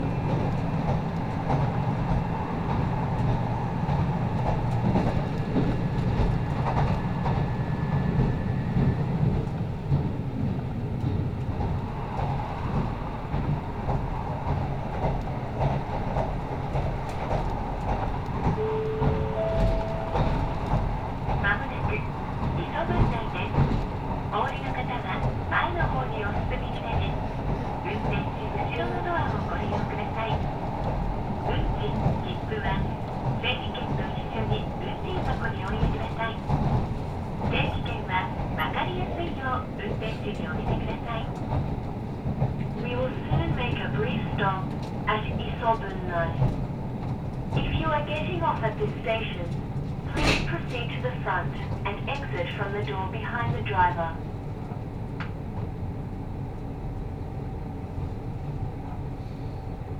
収録日 2021年3月10日 ――眠り揺れる車内、風雪の原野を突き抜けて
キハ54のドアが閉まり、再びディーゼルエンジンが唸りを上げる。
低く響くエンジン音が、まるで子守唄のように車内を包んでいた。